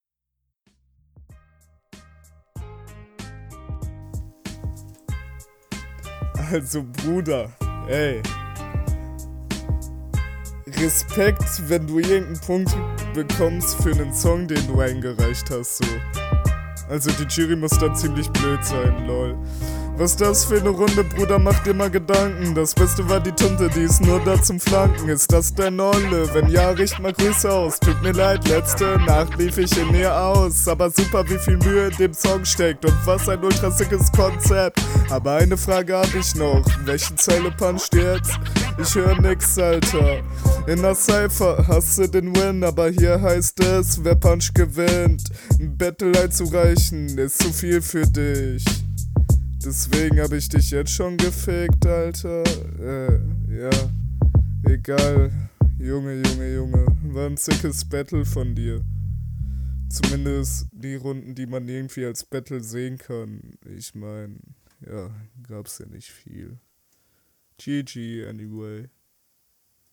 Ich finde deinen Sound nicht so gut, wie von deinem Gegner.